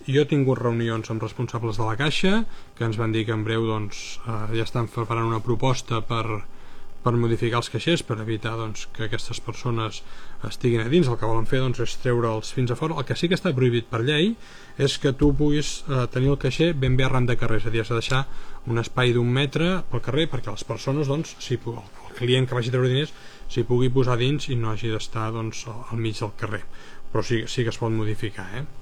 L’alcalde ha explicat que l’Ajuntament ha mantingut converses amb responsables de CaixaBank, l’entitat bancària que gestiona els caixers afectats, i que ja s’està treballant en una reforma dels espais per evitar que s’hi pugui pernoctar: